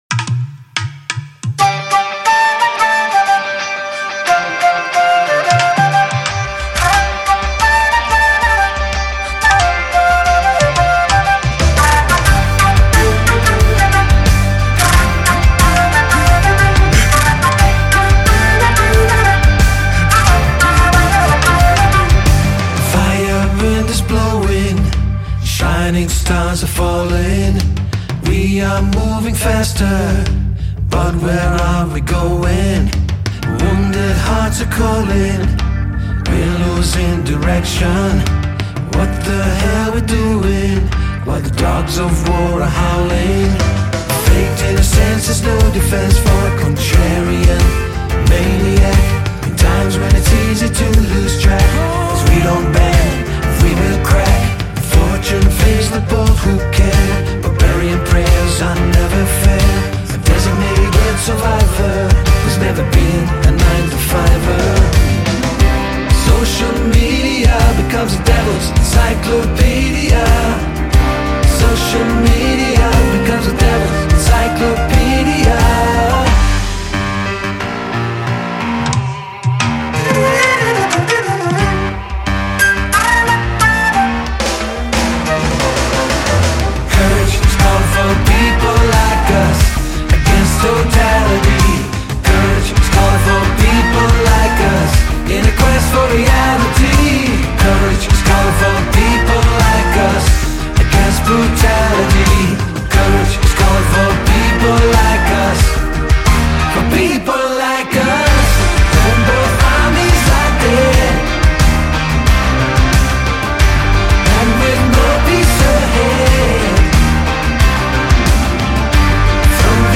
آهنگ راک پراگسیو راک